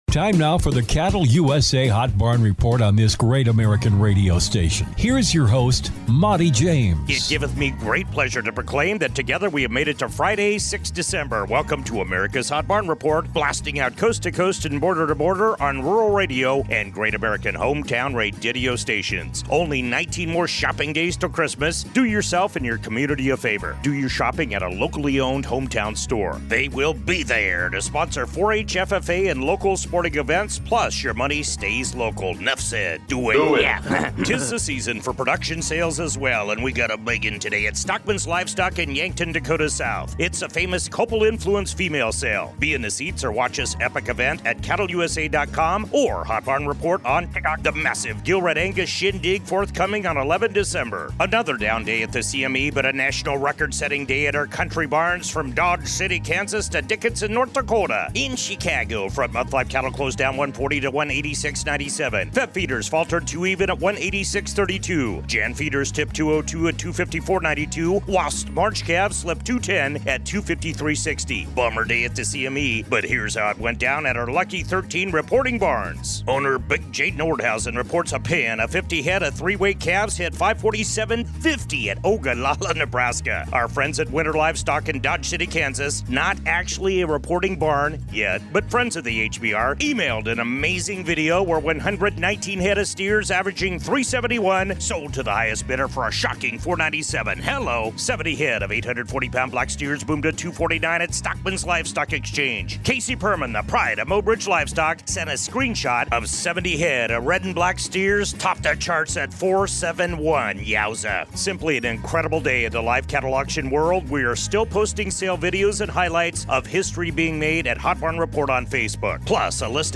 The Hot Barn Report features interviews with industry leaders, market analysts, producers and ranchers and features True Price Discovery from salebarns in Great Northern Beef Belt and across the nation.